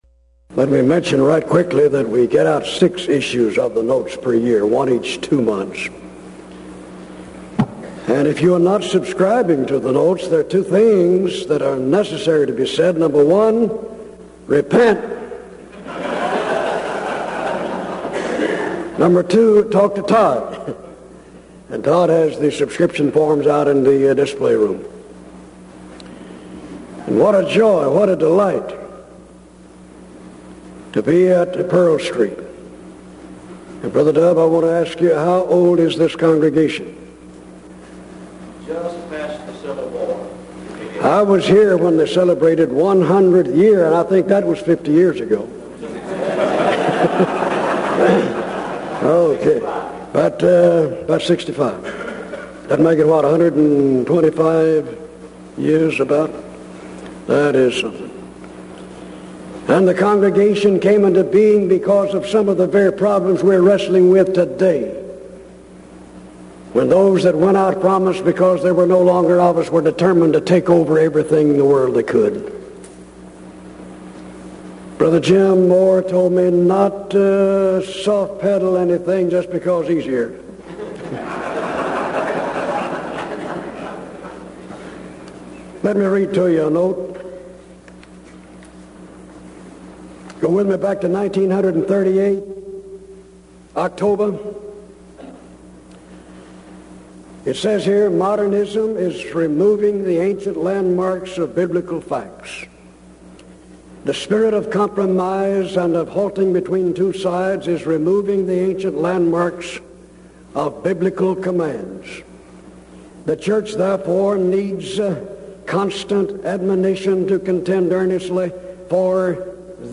Event: 1992 Denton Lectures
lecture